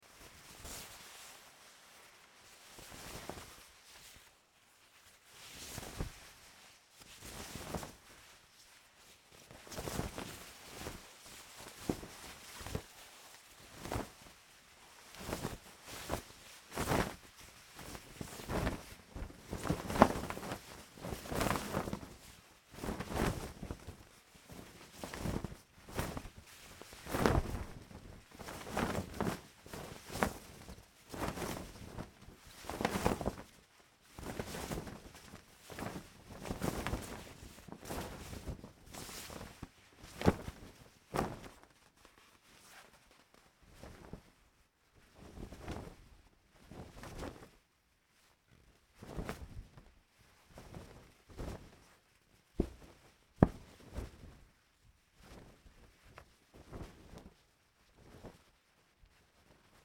Шорох скатерти на столе